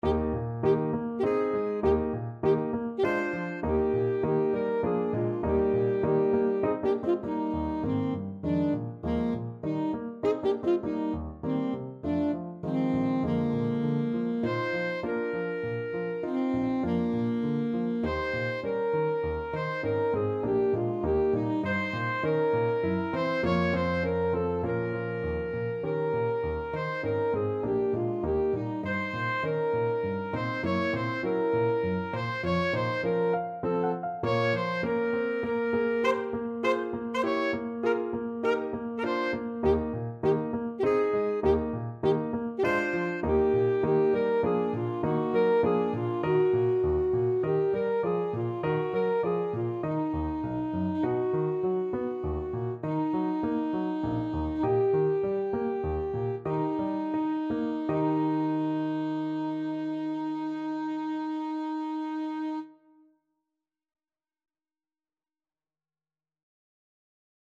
Classical Brahms, Johannes Symphony No.2, 3rd Movement Main Theme Alto Saxophone version
~ = 100 Allegretto grazioso (quasi Andantino) (View more music marked Andantino)
Eb major (Sounding Pitch) C major (Alto Saxophone in Eb) (View more Eb major Music for Saxophone )
3/4 (View more 3/4 Music)
Classical (View more Classical Saxophone Music)